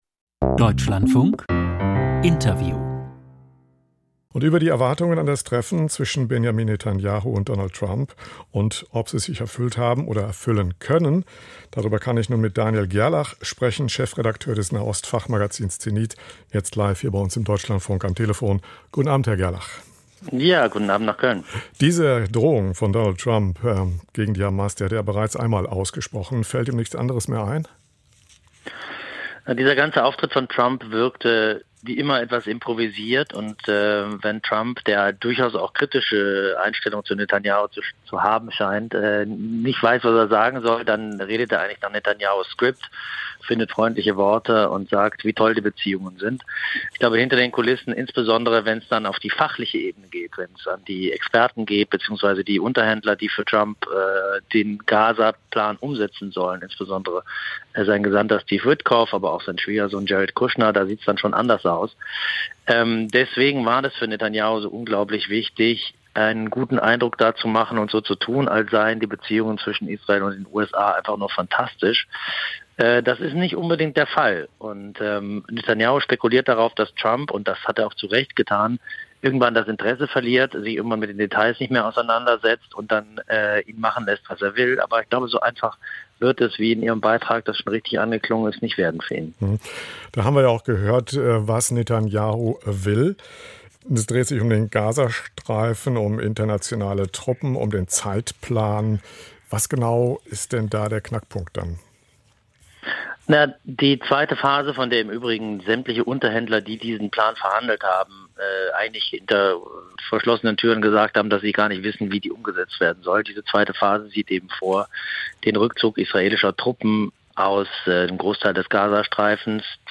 Treffen Netanjahu/Trump - Interview